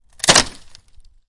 木栅栏的声音 " 啪啪, 木栅栏, N
Tag: 栅栏 开裂 裂缝 开裂 断裂 木材 木方 木板 栅栏 折断 开裂 捕捉 围栏 围栏 打破